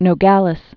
(nō-gălĭs, -gälĭs)